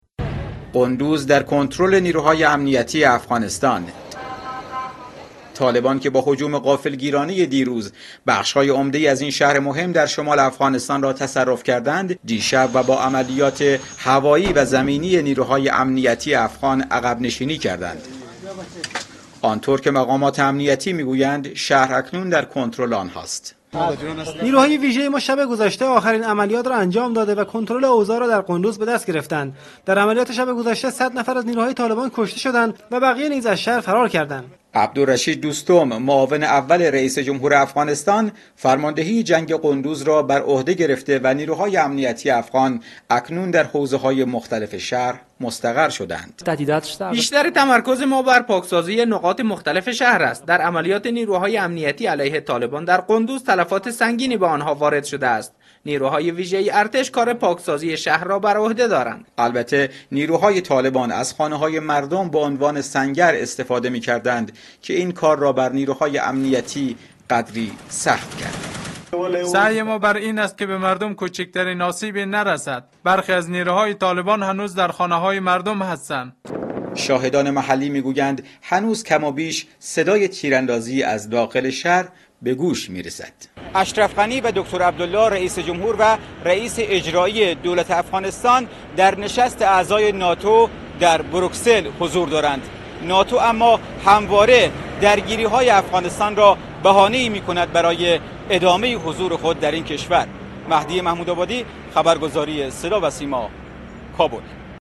аз Кобул гузориш медиҳад .